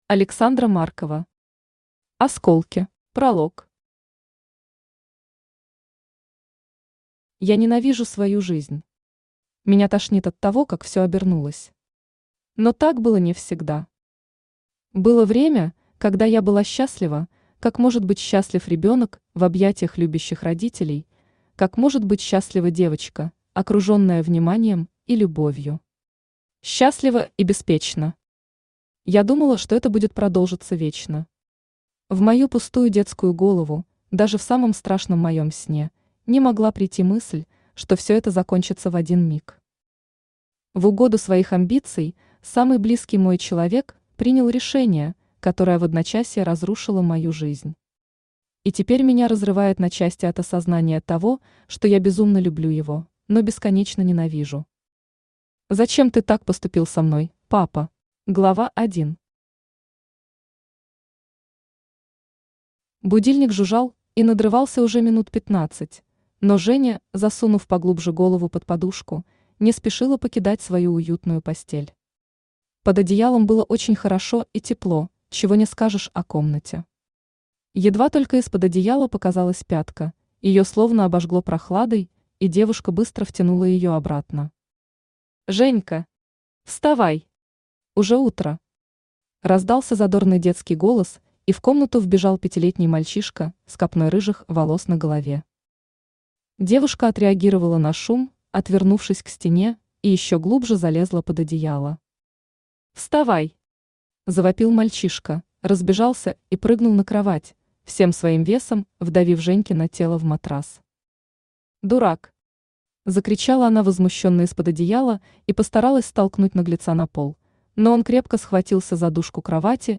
Aудиокнига Третий лишний Автор Александра Маркова Читает аудиокнигу Авточтец ЛитРес.